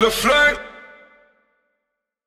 TS Vox_3.wav